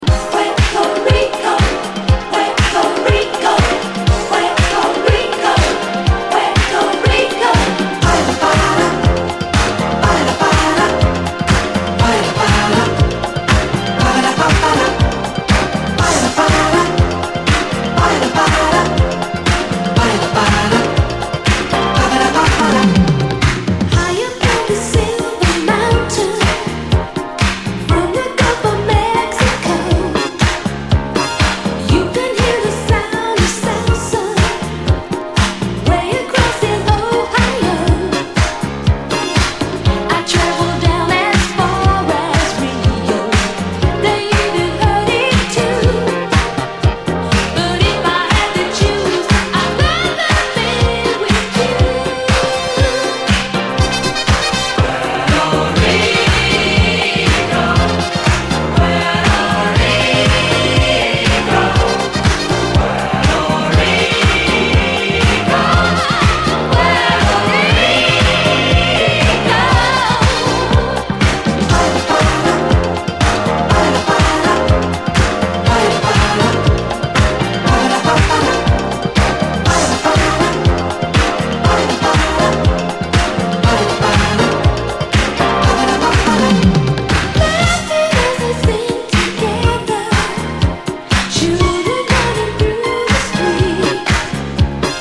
SOUL, DISCO, LATIN
B級ユーロ・ラテン・ディスコ！トロピカルかつ、ユーロ・ディスコのエロティック感あり！